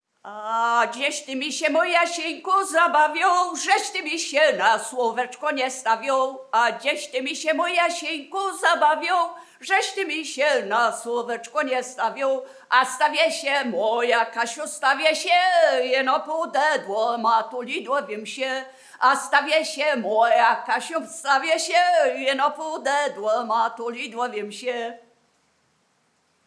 Biskupizna
Wielkopolska
Obyczajowa